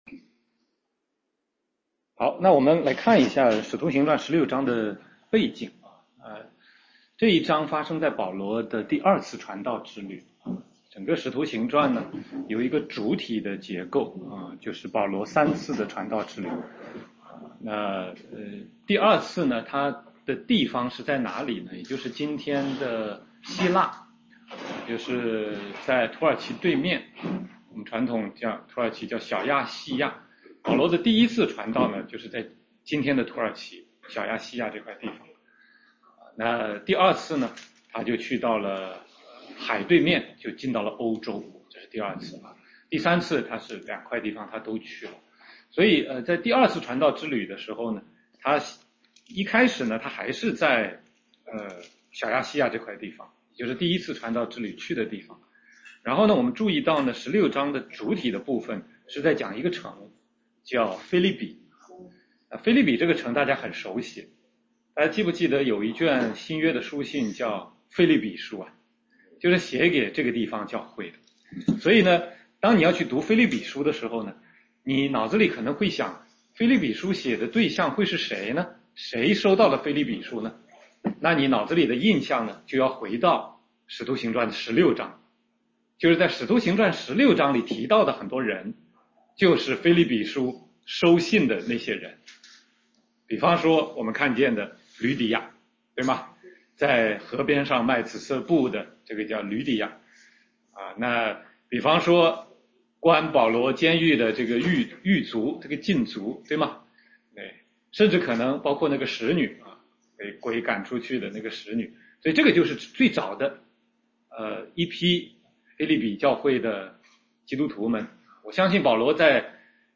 16街讲道录音 - 保罗在腓立比的传道之旅